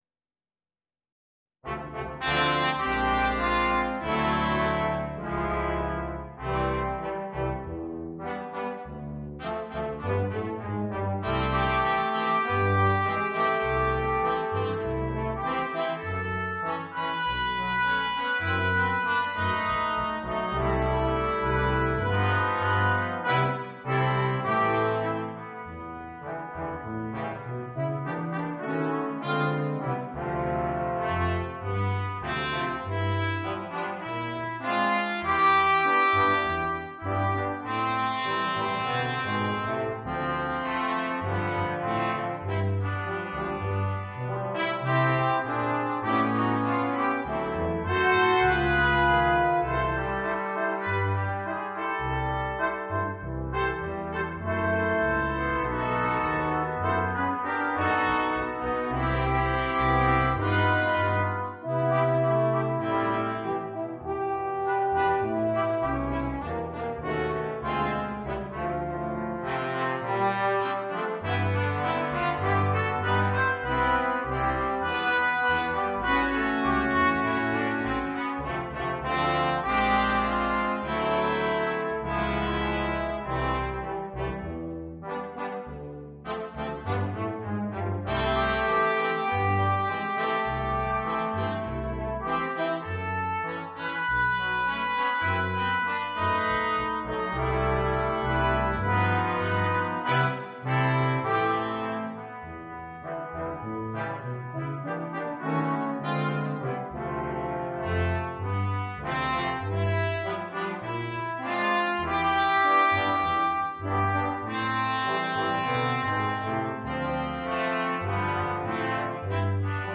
для брасс-квинтета.